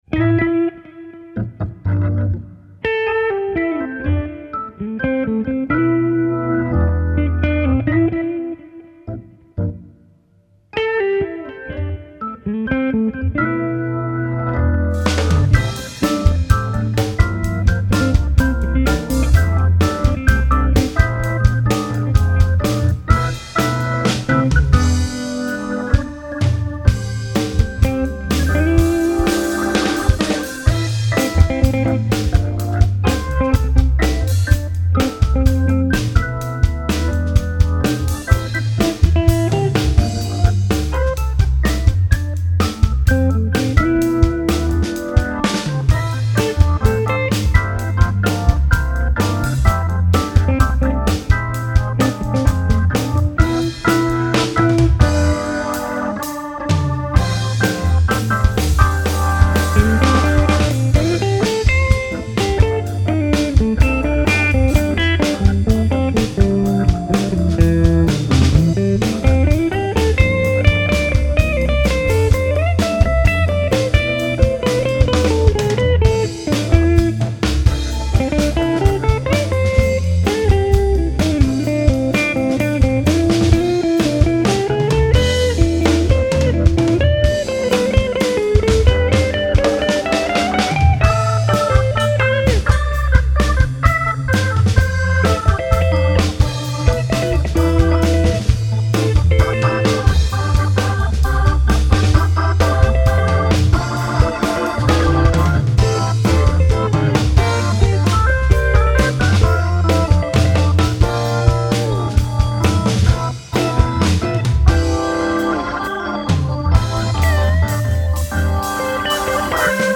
Stavolta niente chitarra distorta emo
quel leslie è una piacevole sorpresa.